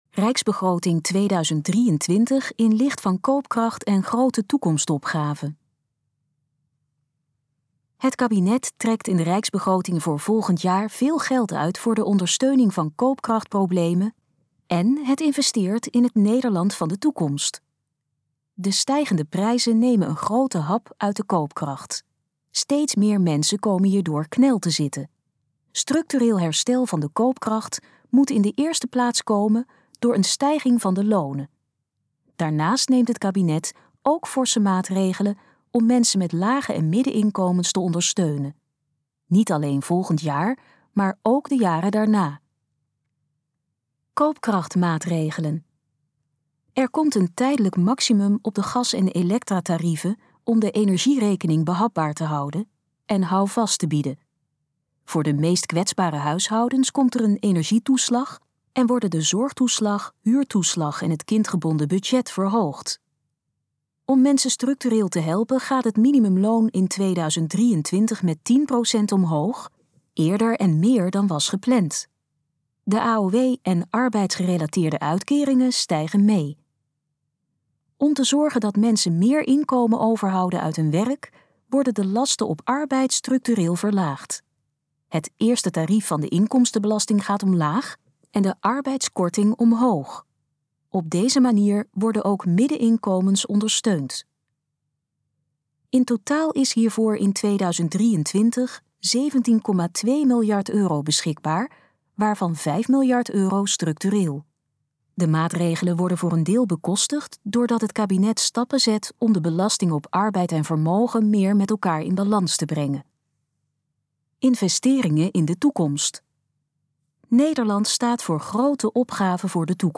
Gesproken versie van de Rijksbegroting 2023 in licht van koopkracht en grote toekomstopgaven
In het volgende geluidsfragment hoort u informatie over de Rijksbegroting 2023. Het fragment is de gesproken versie van de informatie op de pagina Rijksbegroting 2023 in licht van koopkracht en grote toekomstopgaven.